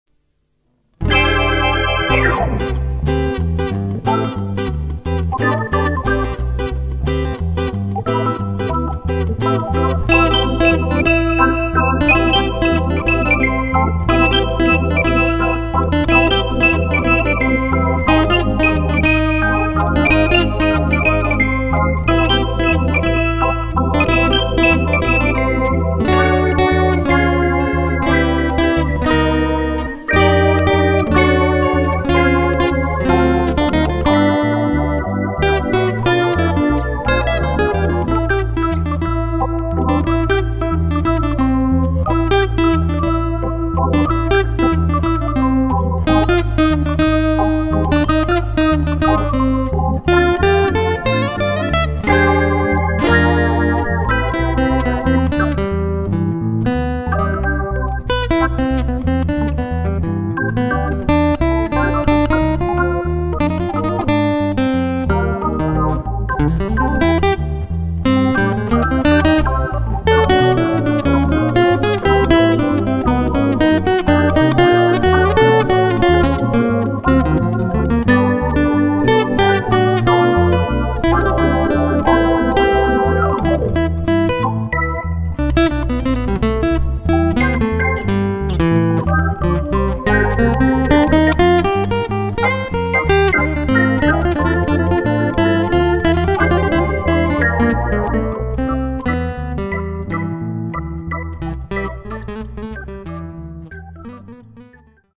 Chitarre e basso
Pianoforte e tastiere (tracce 1-8-11)